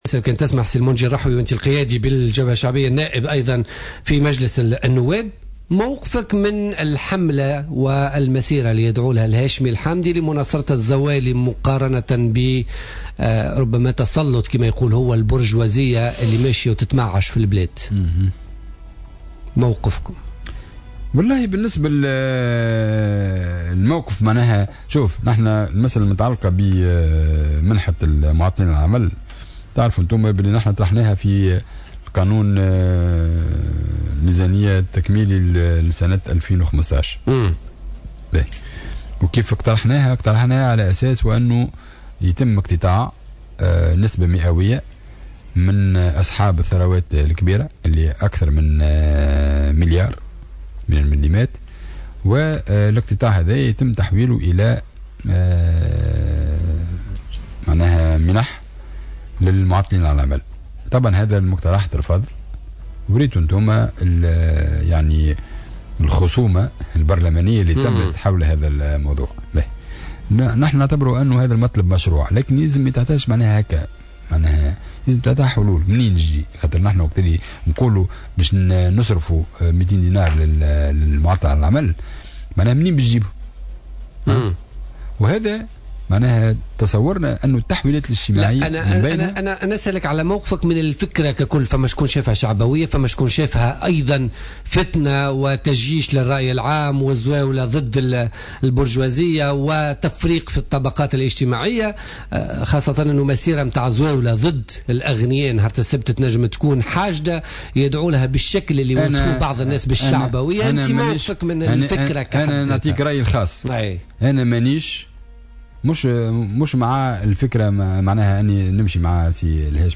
علق النائب بمجلس نواب الشعب والقيادي في الجبهة الشعبية منجي الرحوي في تصريح للجوهرة أف أم في برنامج بوليتكا لليوم الخميس 29 أكتوبر 2015 على المسيرة التي دعا رئيس حزب تيار المحبة الهاشمي الحامدي إلى تنظيمها السبت القادم لنصرة الزوالي.